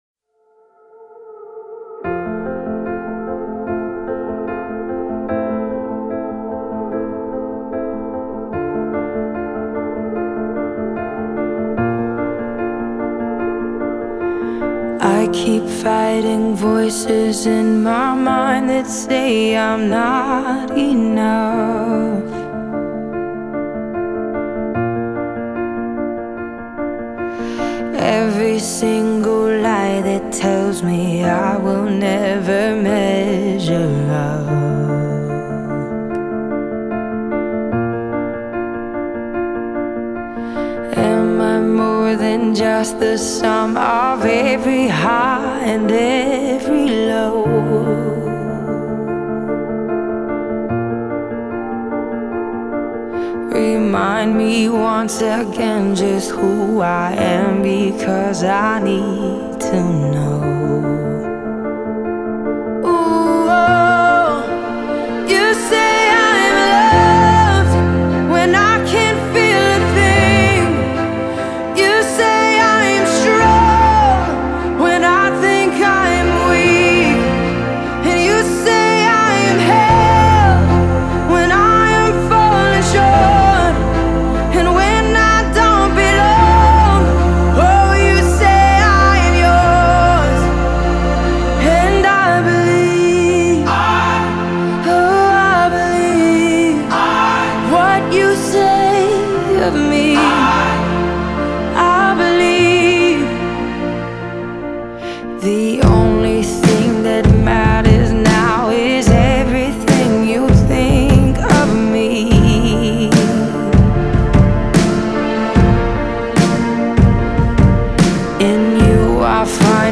Genre : Christian & Gospel